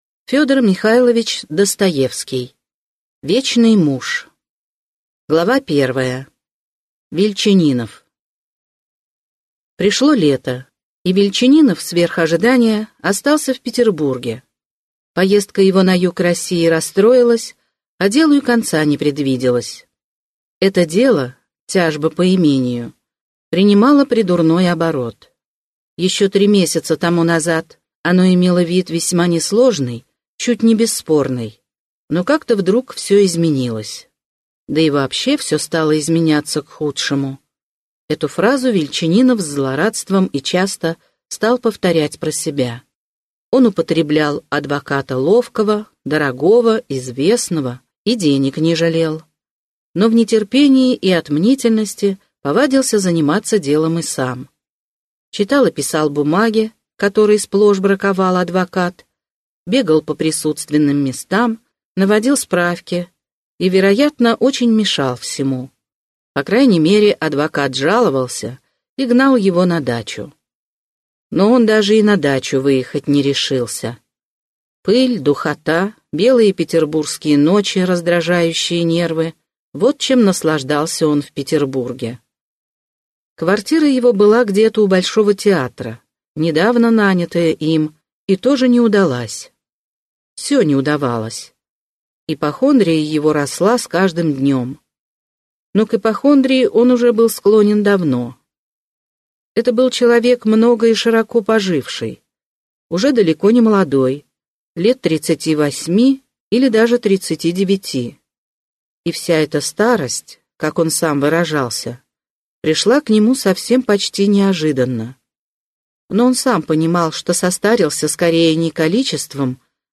Аудиокнига Вечный муж | Библиотека аудиокниг